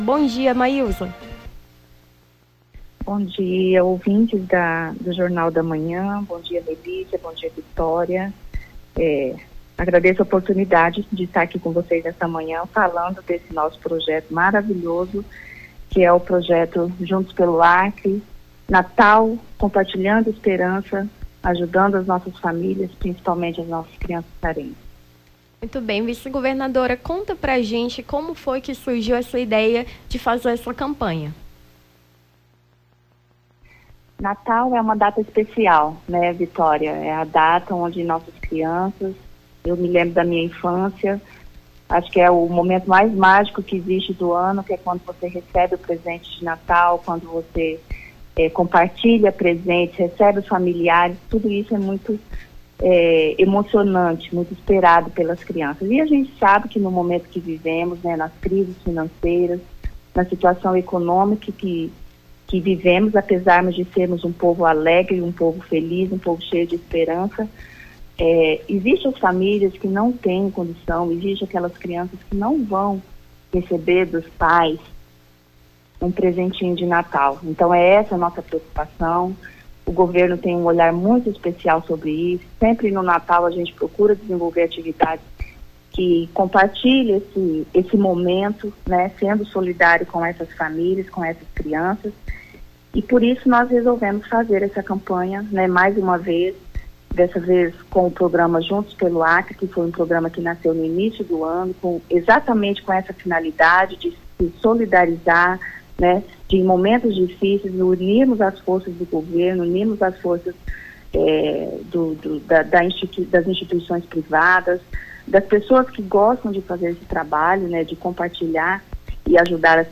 Nome do Artista - CENSURA - ENTREVISTA (CAMPANHA NATAL COMPARTILHANDO ESPERANCA) 19-12-23.mp3